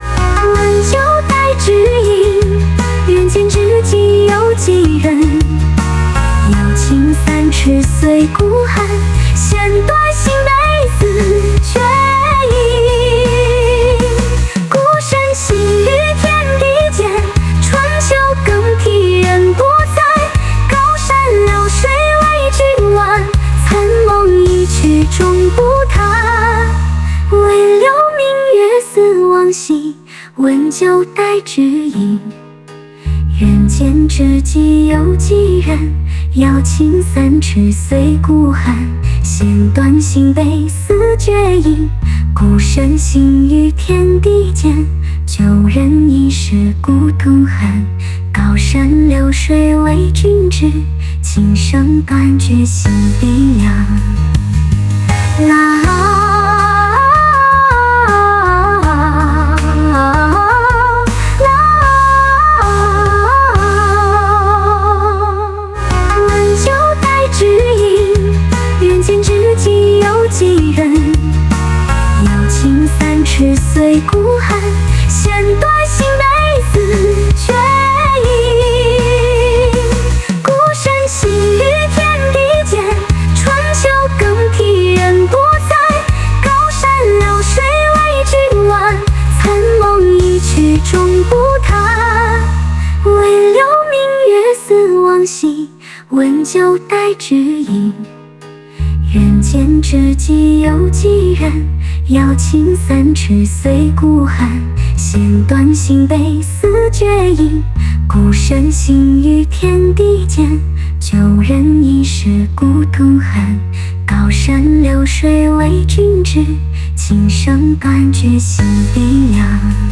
Ps：在线试听为压缩音质节选，体验无损音质请下载完整版 温酒待知音 人间知己有几人？